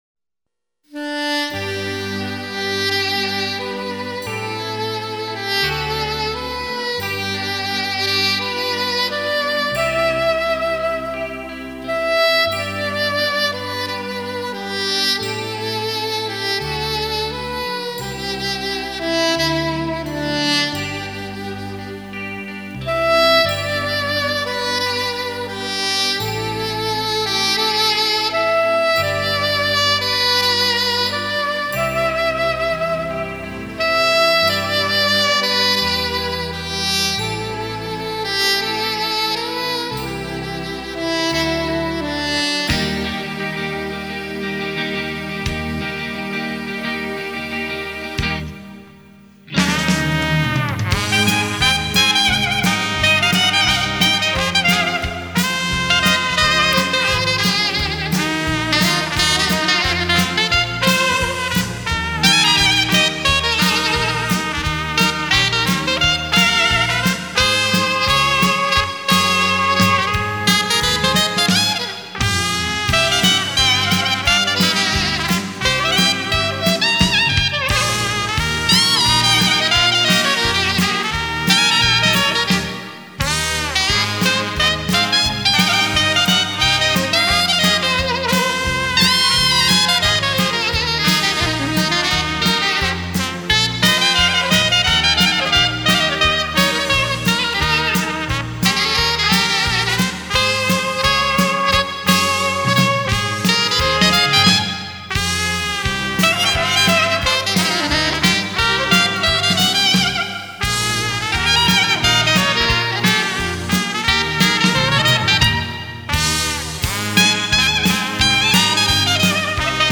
喜欢萨克斯妩媚的音色感觉很飘然...